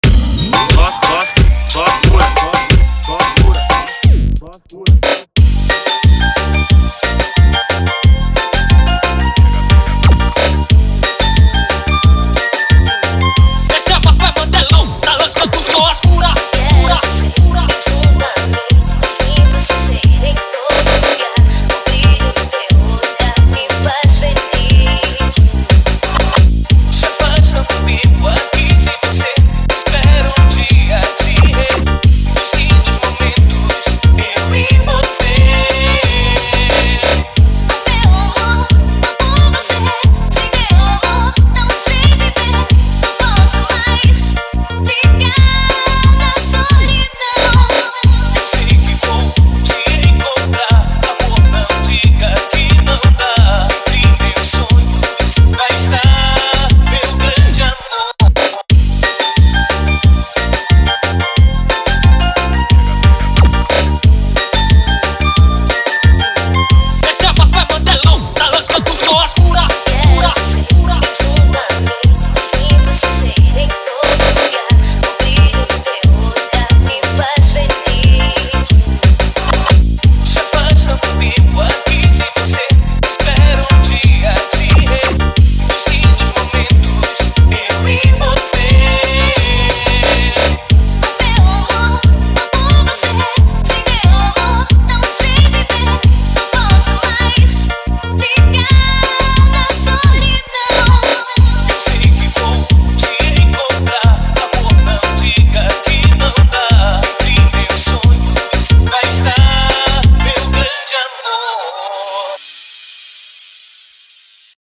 OUÇA NO YOUTUBE Labels: Tecnofunk Facebook Twitter